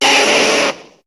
Cri de Reptincel dans Pokémon HOME.